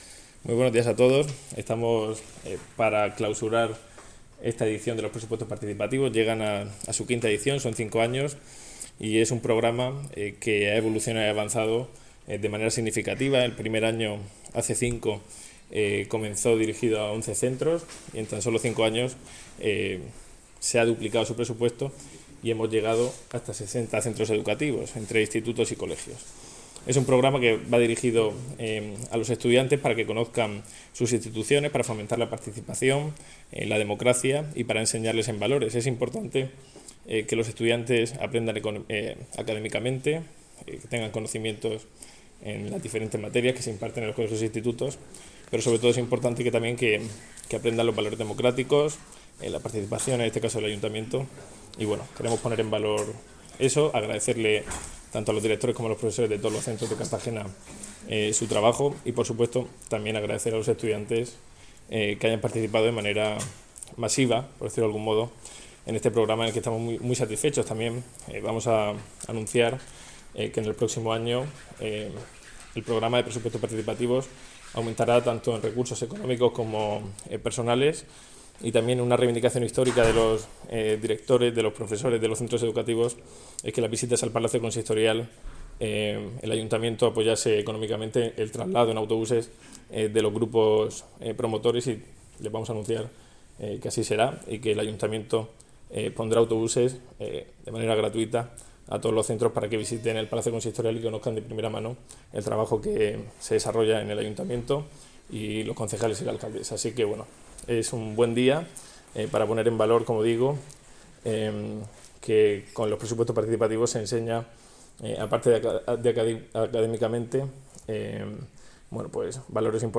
Enlace a Declaraciones de Nacho Jáudenes en la clausura de los presupuestos participativos